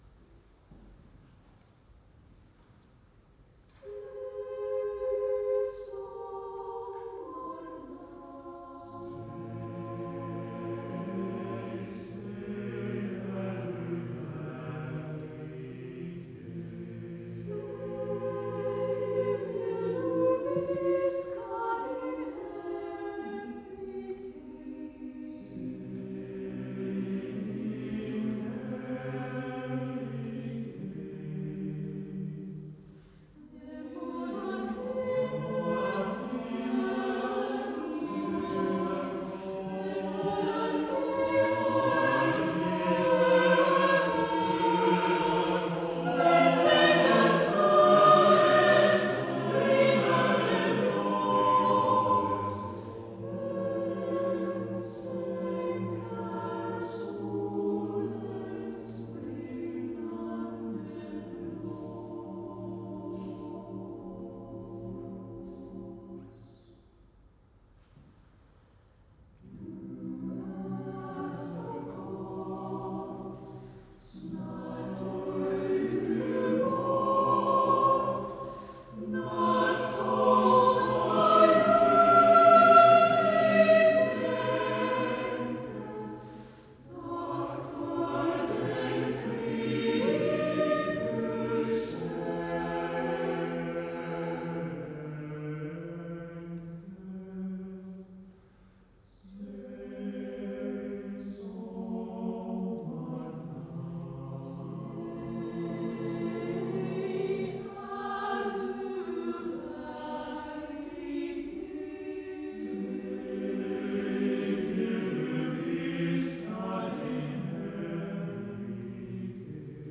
Sopranen
Tenoren
Bassen / baritons